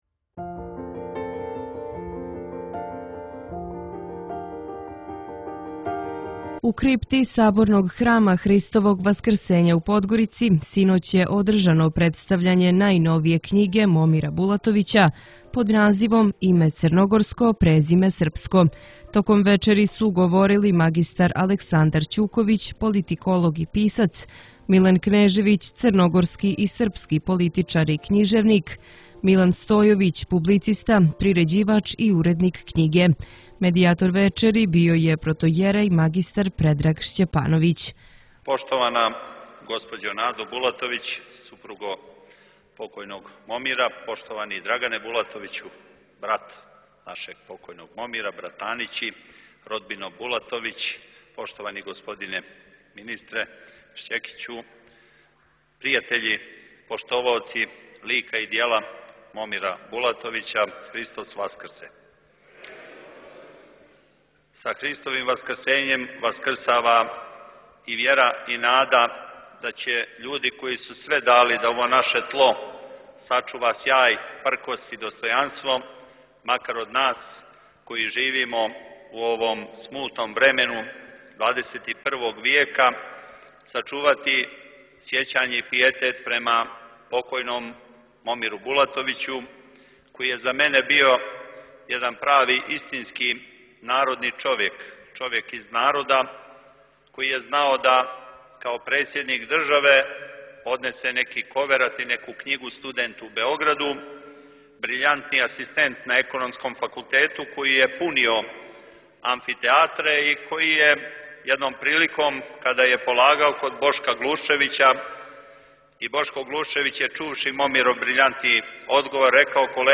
Извјештаји • Радио ~ Светигора ~